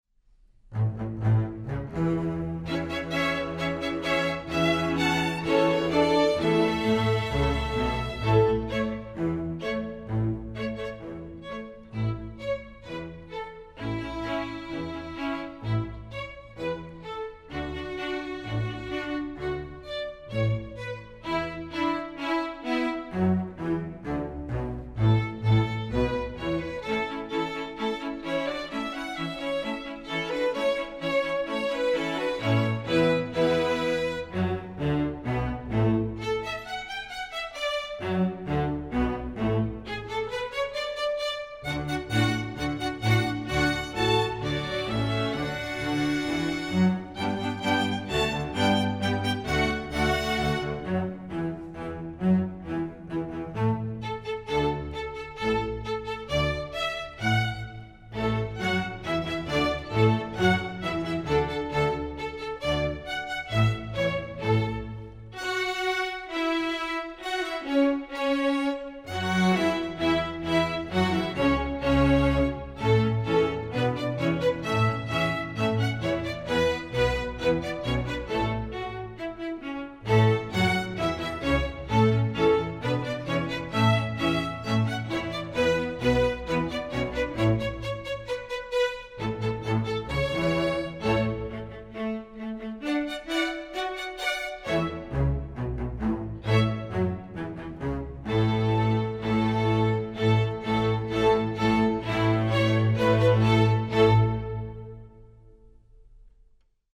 instructional, children
Piano accompaniment part: